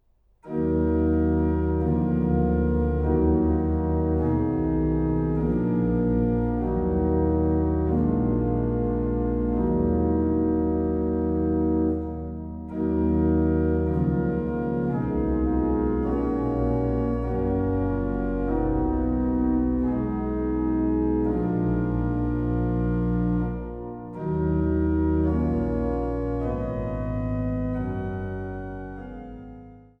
an der Großen Silbermann-Orgel im Freiberger Dom
Orgel